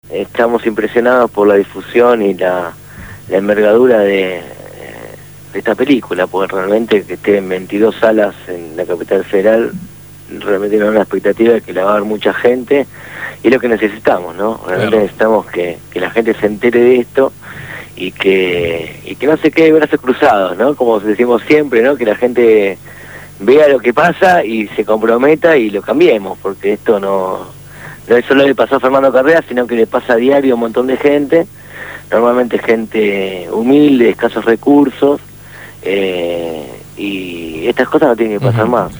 Hoy fue entrevistado